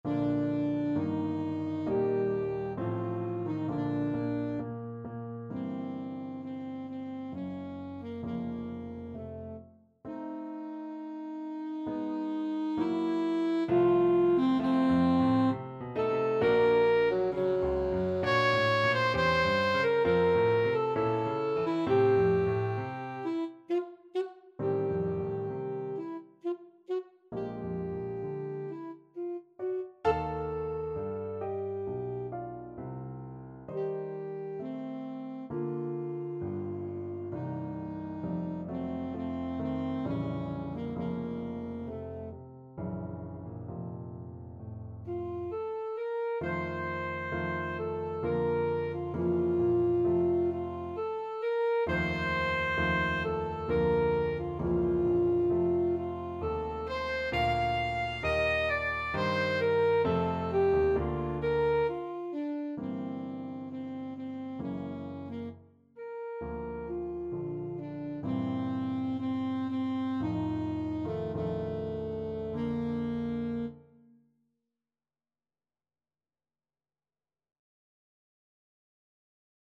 Andante Sostenuto, Main Theme Alto Saxophone version
Bb major (Sounding Pitch) G major (Alto Saxophone in Eb) (View more Bb major Music for Saxophone )
3/4 (View more 3/4 Music)
=66 Andante sostenuto
Classical (View more Classical Saxophone Music)